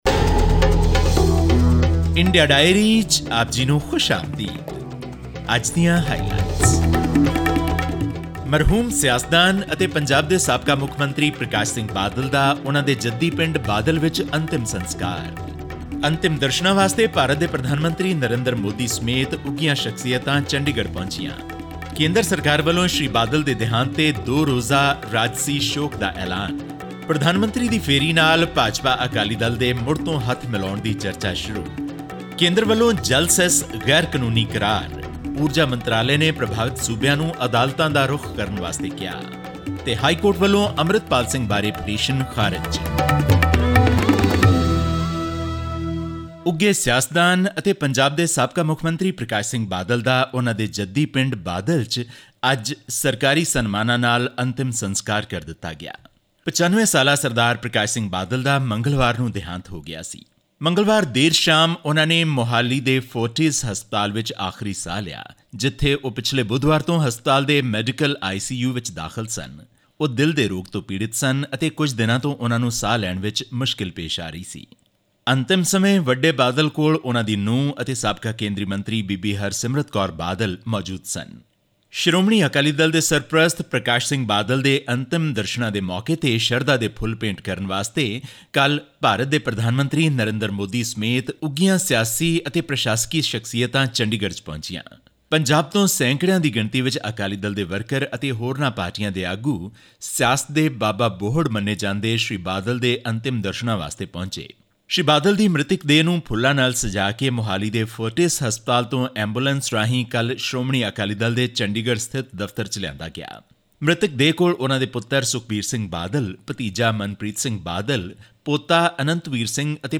ਹੋਰ ਵੇਰਵੇ ਲਈ ਇਹ ਆਡੀਓ ਰਿਪੋਰਟ ਸੁਣੋ...